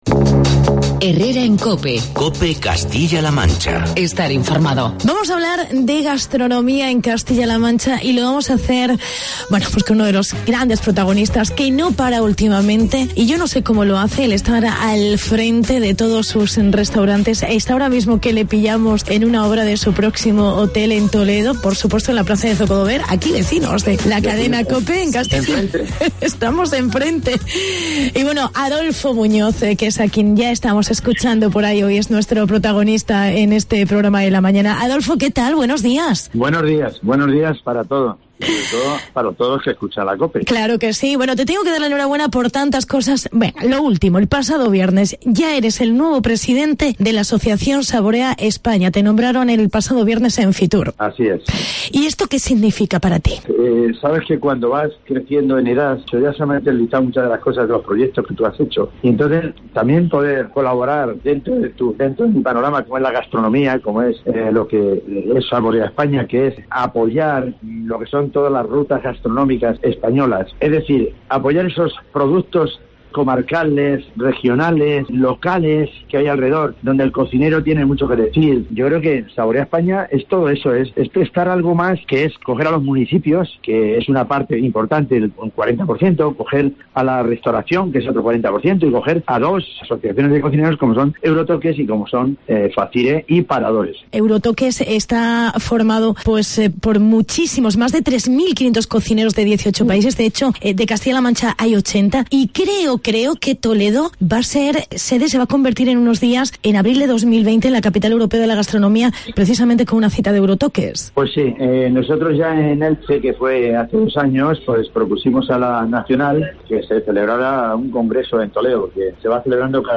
Entrevistamos al chef toledano